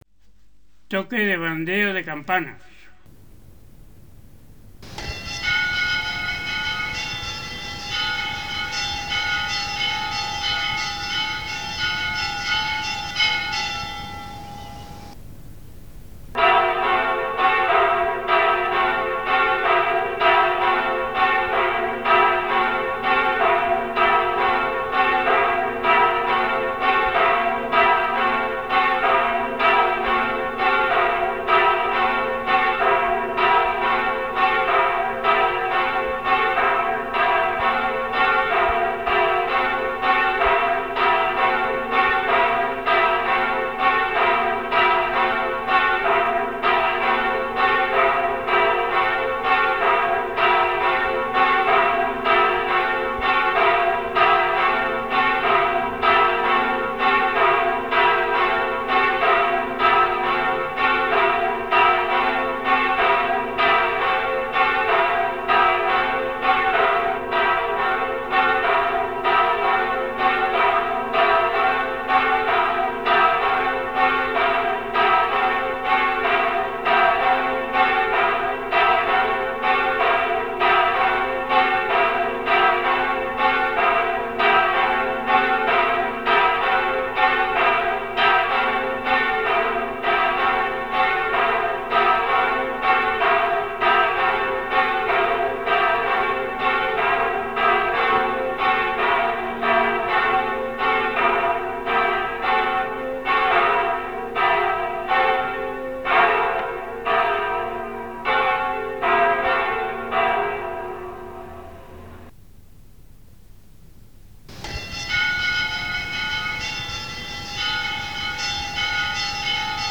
Sonidos de Toques de Campana en Sangüesa
06 Toque de Bandeo
06_Toque_de_Bandeo.m4a